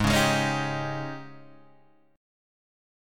G# Major 7th Suspended 4th